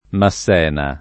vai all'elenco alfabetico delle voci ingrandisci il carattere 100% rimpicciolisci il carattere stampa invia tramite posta elettronica codividi su Facebook Massena [ ma SS$ na ] (francesizz. Masséna [ ma S en # ]) cogn.